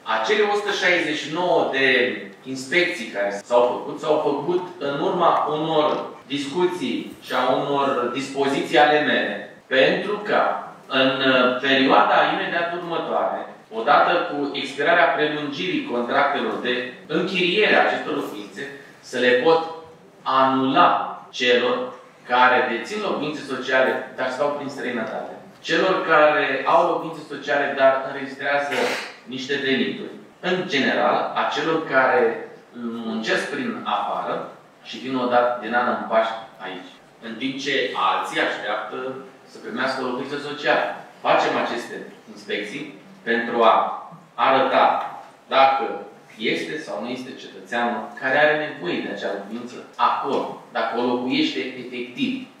Azi, 27 februarie 2025, Consiliul Local Tulcea s-a reunit într-o nouă ședință ordinară pentru a discuta o serie de proiecte ce vizează, printre altele, administrarea domeniului public, cofinanțarea serviciilor sociale destinate persoanelor fără adăpost și măsuri fiscale pentru contribuabilii aflați în dificultate.
În replică, Primarul Ștefan Ilie a menționat că, deși există peste 6.000 de cereri pentru locuințe sociale, resursele Primăriei sunt limitate, însă se caută soluții pentru ca acestea să ajungă la cei care au cu adevărat nevoie.